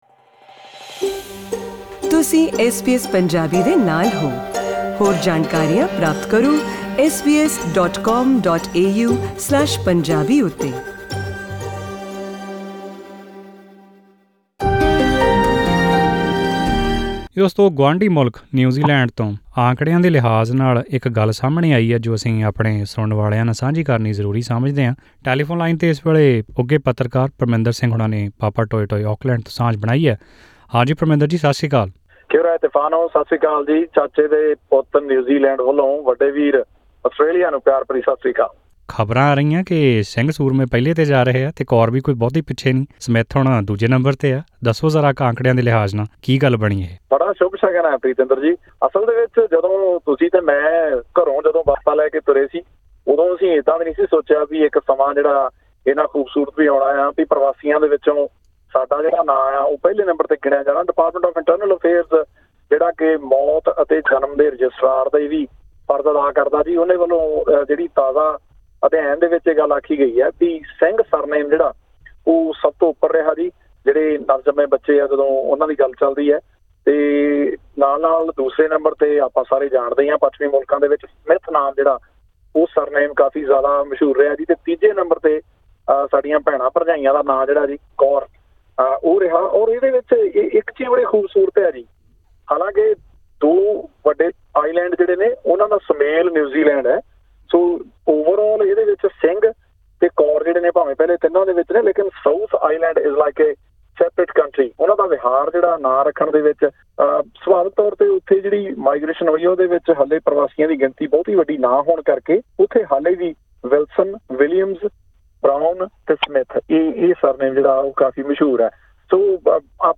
Listen to this audio report for more information…